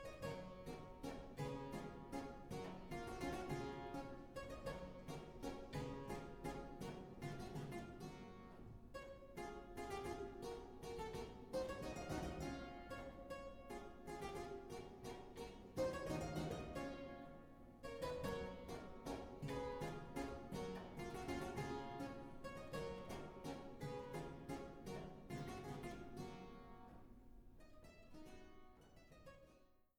Fortepiano und Clavichord